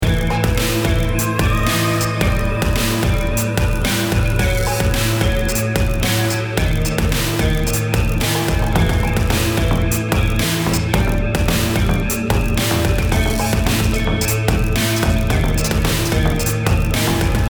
BPM 110